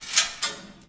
CHAIN_Remove_01_mono.wav